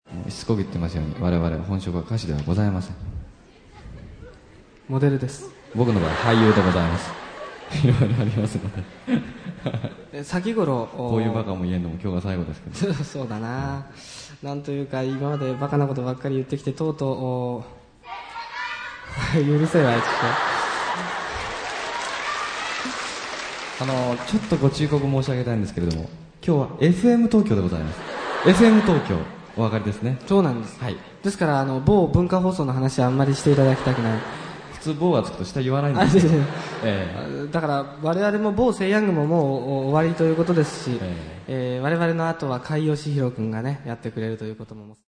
ライブアルバム
1976年3月26日　神田共立講堂にて収録